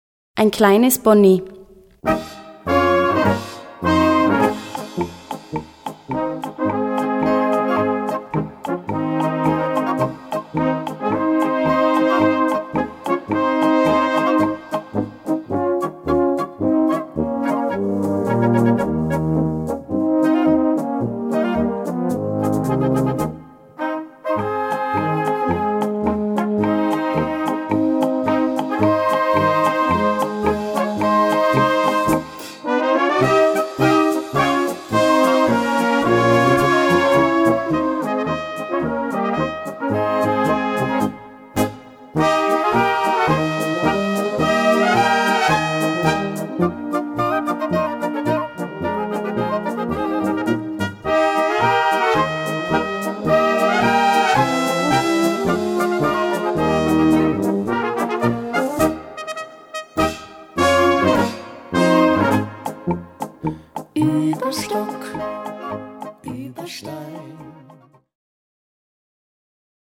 Gattung: Polka mit Gesang
Besetzung: Blasorchester